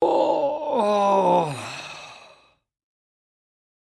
Male Stretch and Groan
SFX
yt_VPn8IAaLqYo_male_stretch_and_groan.mp3